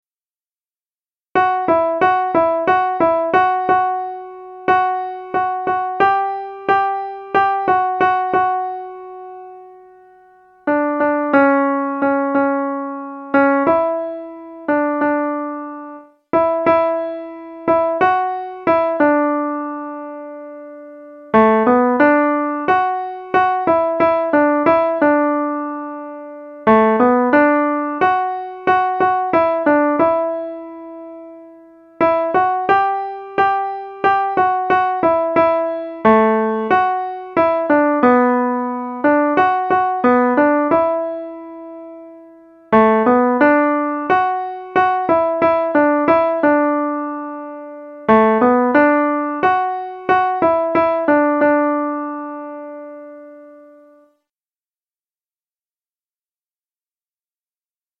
Jubileum 2019 Alter
1.og 2.Alt: